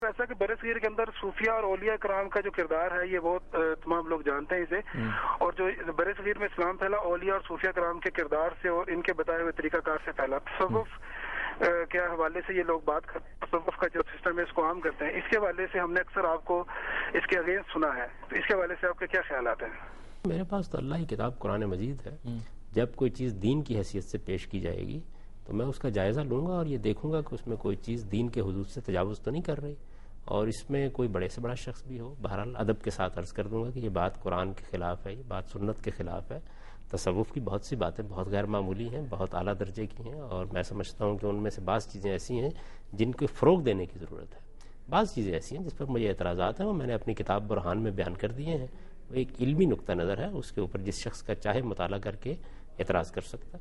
Answer to a Question by Javed Ahmad Ghamidi during a talk show "Deen o Danish" on Duny News TV
دنیا نیوز کے پروگرام دین و دانش میں جاوید احمد غامدی ”اشاعت اسلام اور صوفیا ء کا کردار “ سے متعلق ایک سوال کا جواب دے رہے ہیں